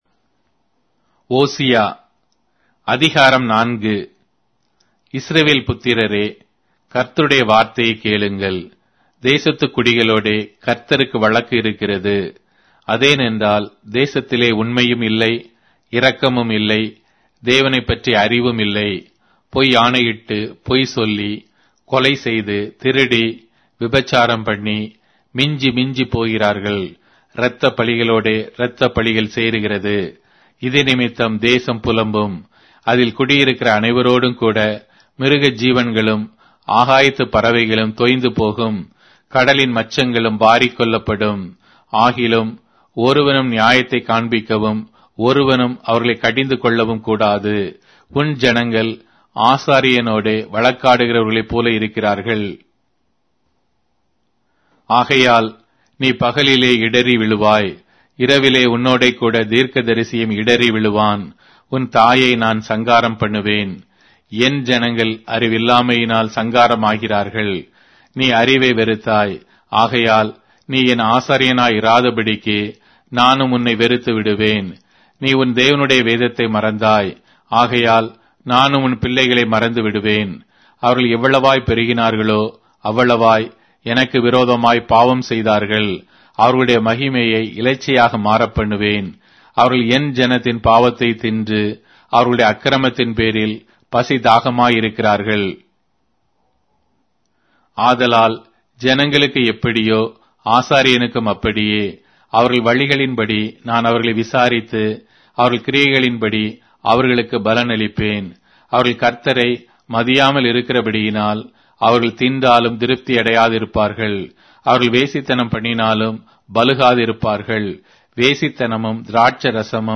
Tamil Audio Bible - Hosea 4 in Ervml bible version